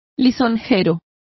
Complete with pronunciation of the translation of flatterer.